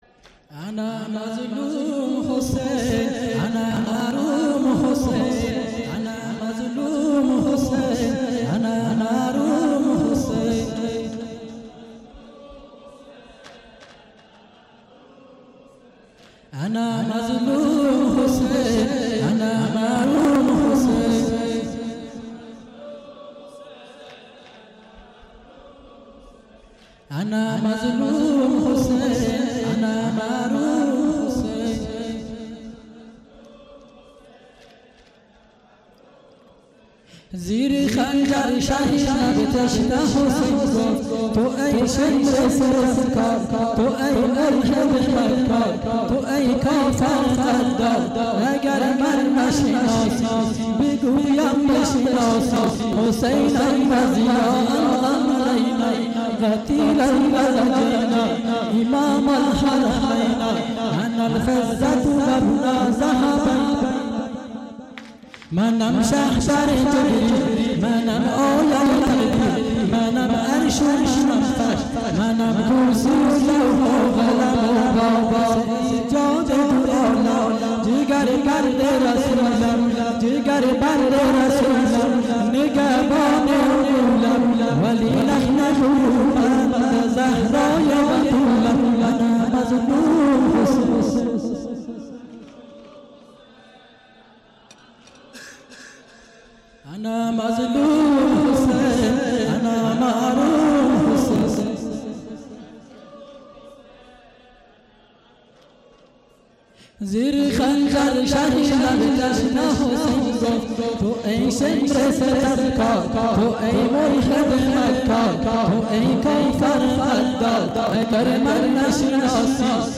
شب سوم محرم98 هیئت میثاق الحسین (ع) سیستان